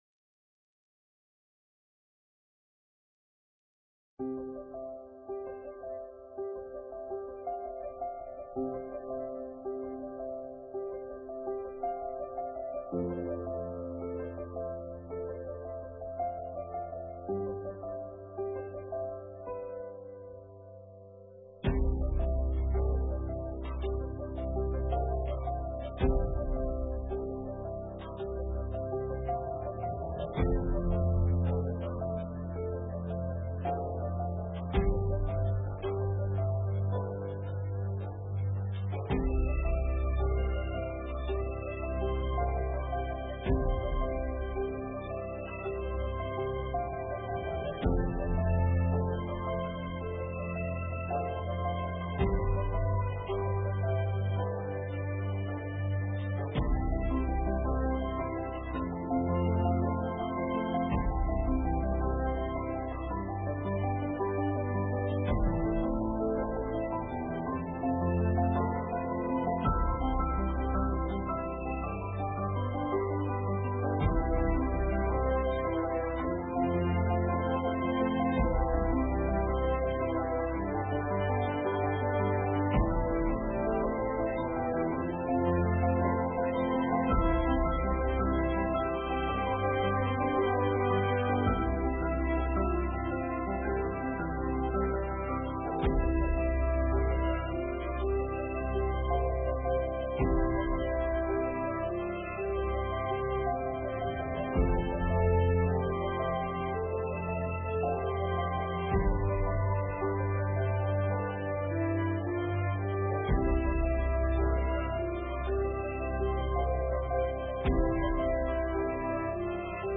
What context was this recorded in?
The Celebration of the Gospel May 10, 2020 – The Fifth Sunday of Easter